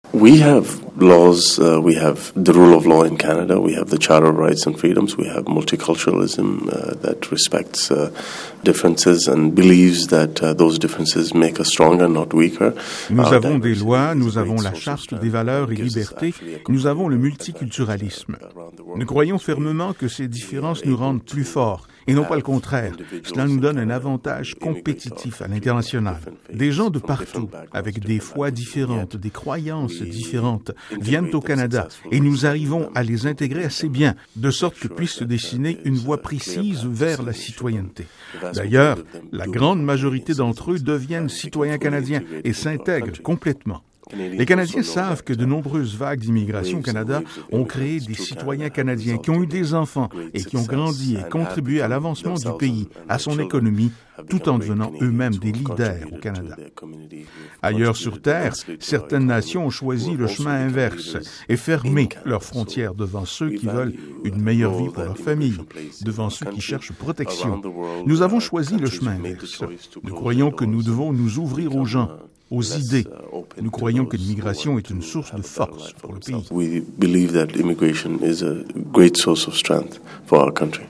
À titre de premier titulaire du ministère de l’Immigration du Canada à être musulman, monsieur Hussen nous parle de ces résultats.